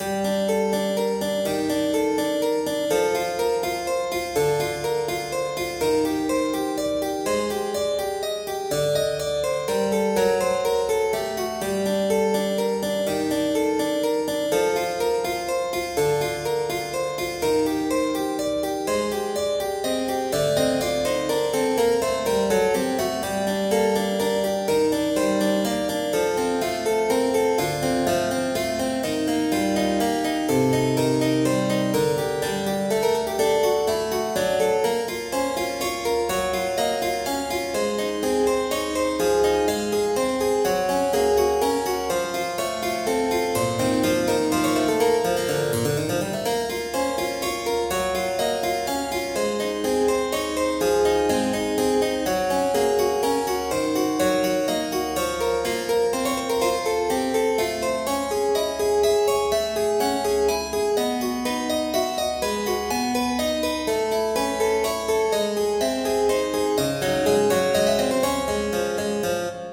ファンタジー系フリーBGM｜ゲーム・動画・TRPGなどに！
転調はエグイ。